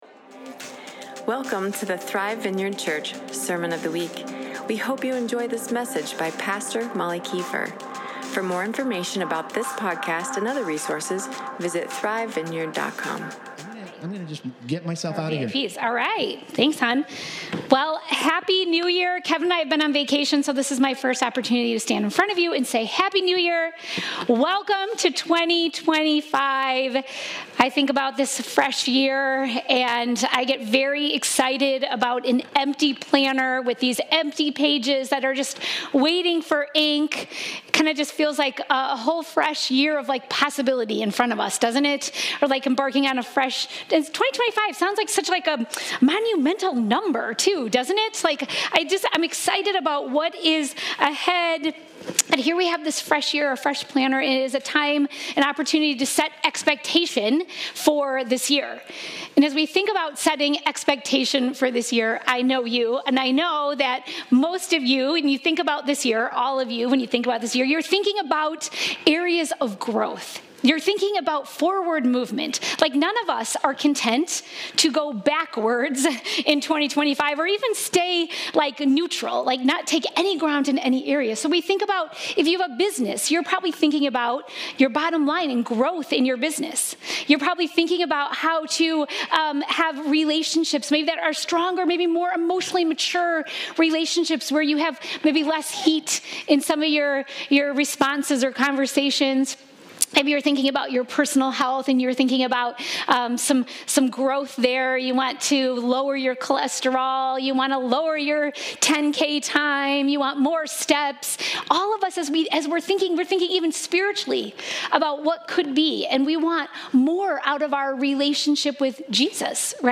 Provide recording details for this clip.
2025 Daniel 1 Isaiah 58:11-12 Matthew 4:2-4 Matthew 6:16-18 Sunday Service Feeling stuck